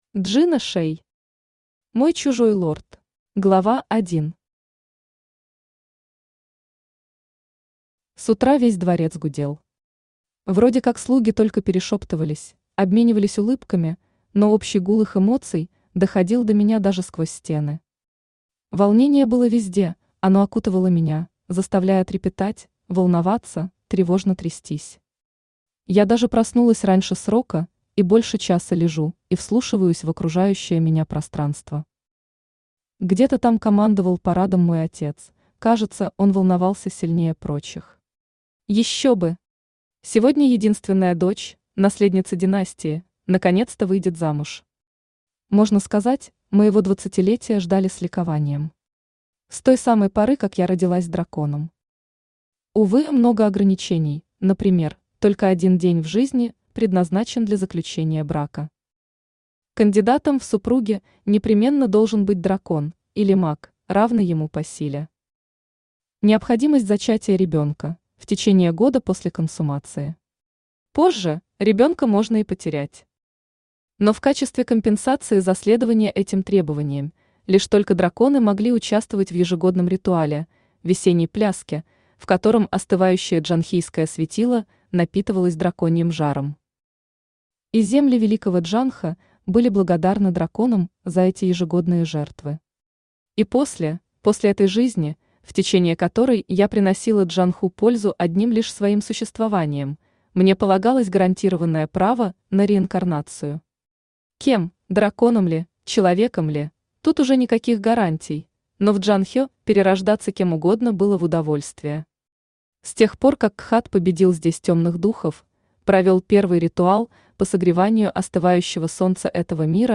Aудиокнига Мой чужой лорд Автор Джина Шэй Читает аудиокнигу Авточтец ЛитРес.